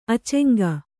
♪ aceŋga